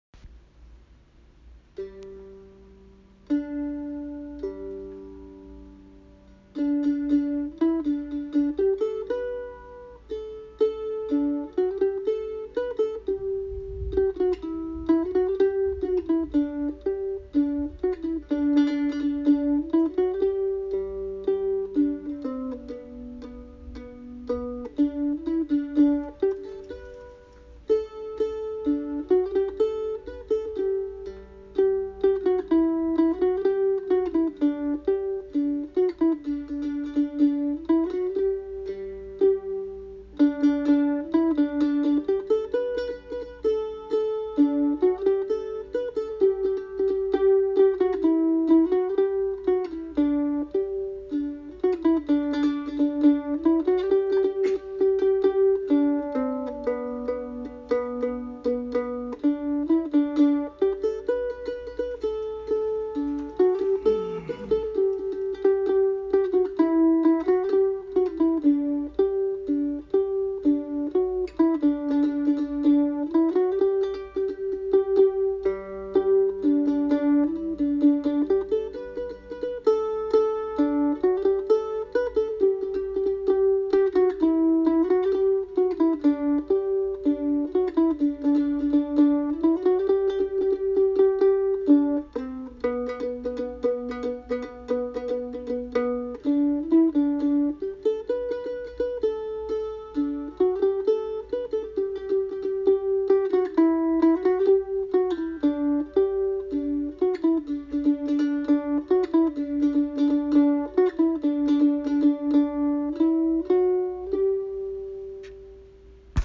Played on my Ratliff mandolin.
Custom Ratliff Mandolin
Church-in-the-wildwood-mandolin-.mp3